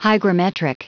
Prononciation du mot hygrometric en anglais (fichier audio)
Prononciation du mot : hygrometric